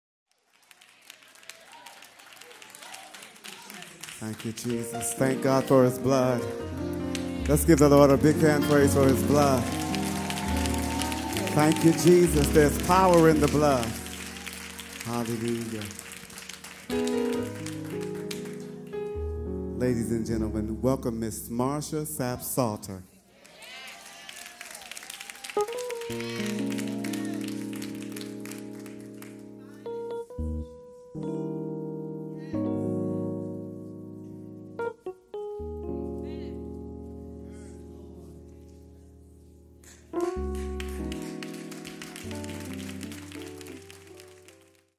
Live at the South Orange Performing Arts Center.